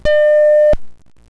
snd_32440_Original beep.wav